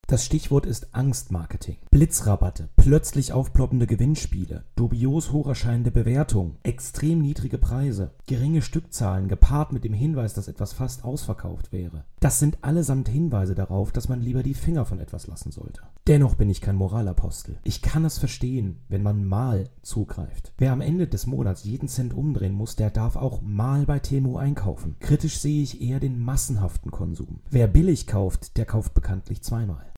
Er ist Experte für Psychologie im Marketing.